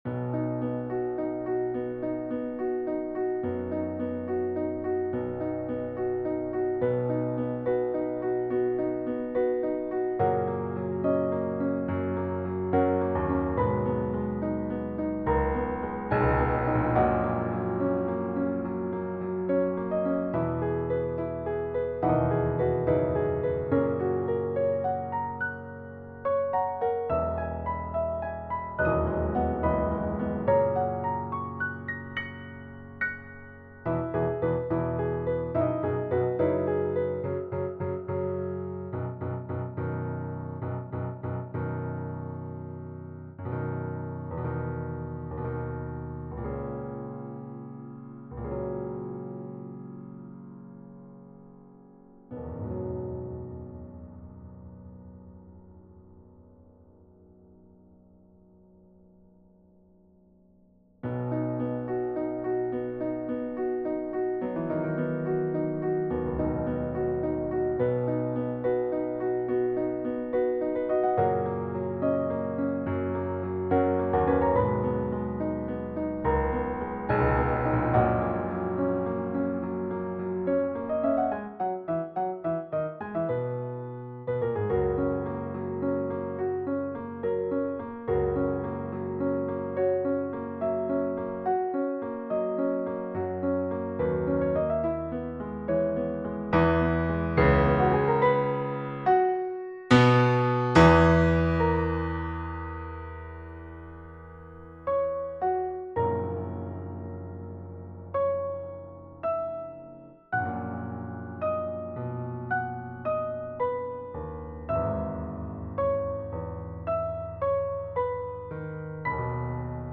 Also, I hope my use of the pedal was not excessive.